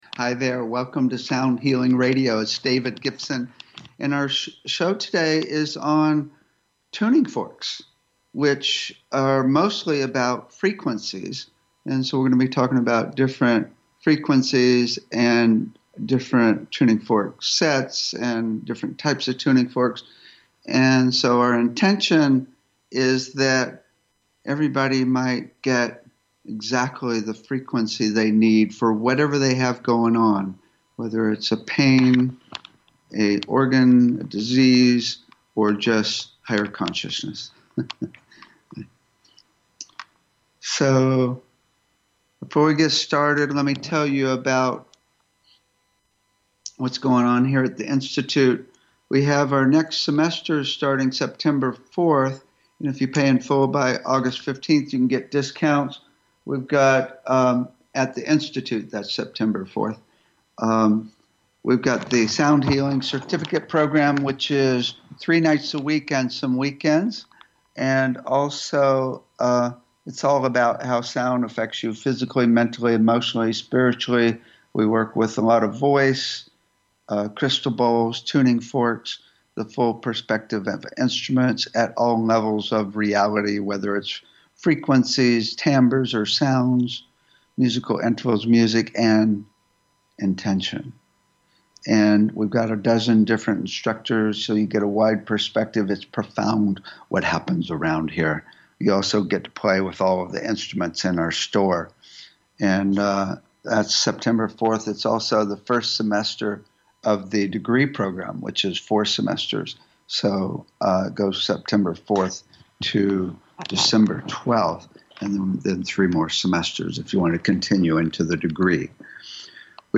Talk Show Episode, Audio Podcast, Sound Healing and Tuning Forks, frequencies and tuning fork sets on , show guests , about Sound Healing,Tuning Forks; frequencies and tuning fork sets, categorized as Education,Health & Lifestyle,Sound Healing,Kids & Family,Physics & Metaphysics,Psychology,Self Help,Spiritual,Technology